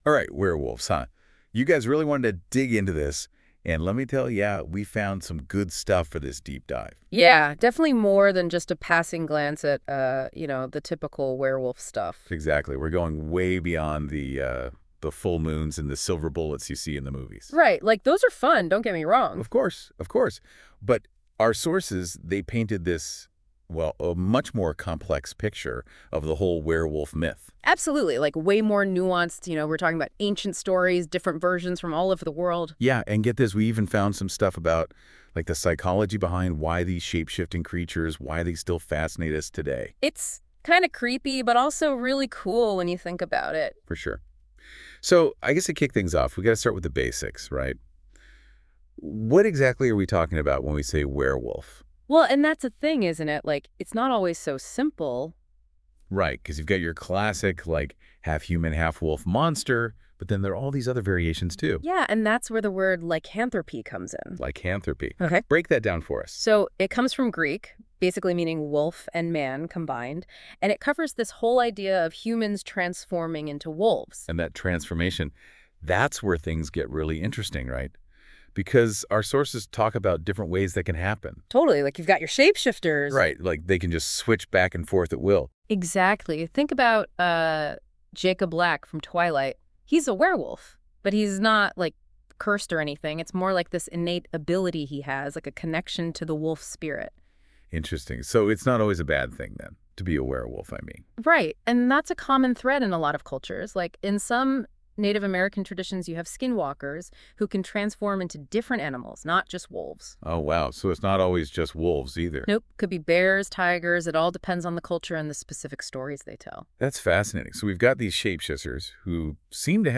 Discussion / Podcast on Werewolves
Let’s listen to a discussion about Werewolf / Werewolves. Werewolf Tales from Around the World: A Global Exploration of Lycanthropy